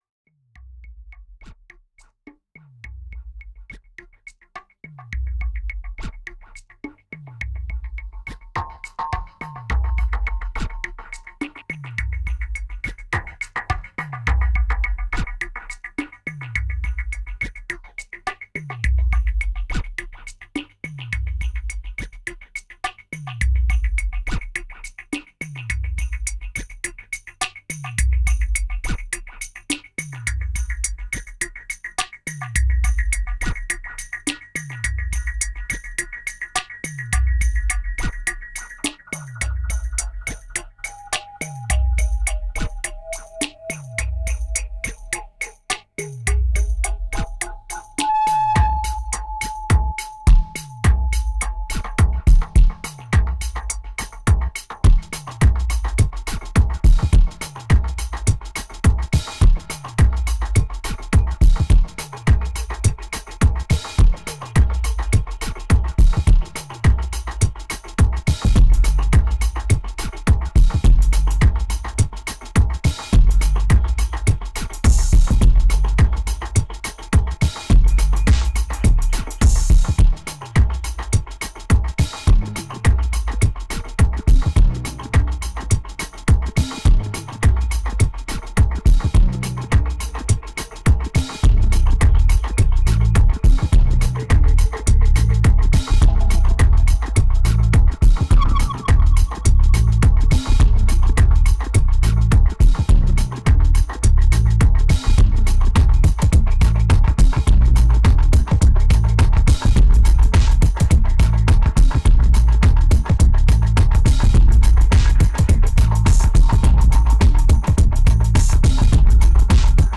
It's raw, it's dirty and it's lame, but are you up to it ?